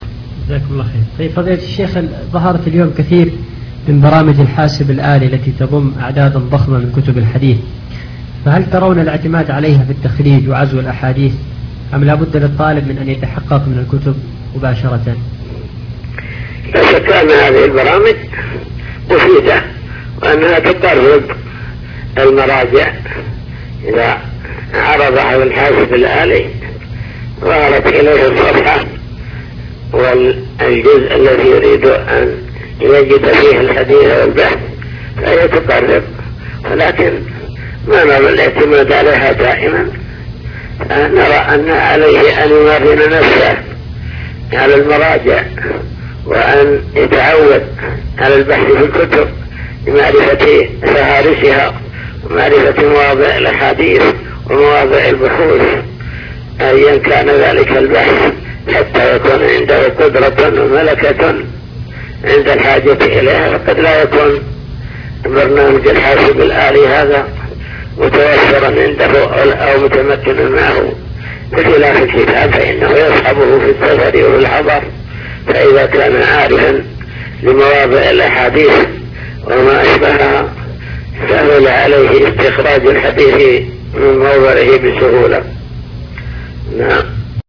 تسجيلات - لقاءات